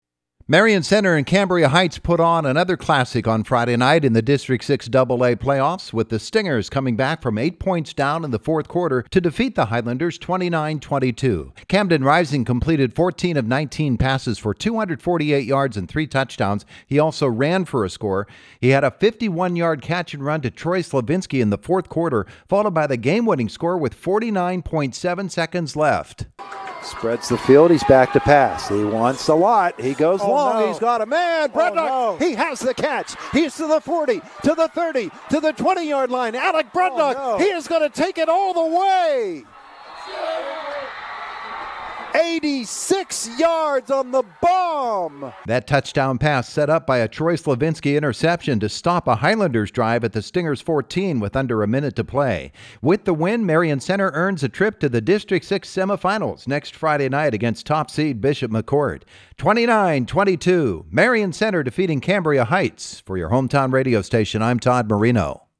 had the recap on Renda Digital TV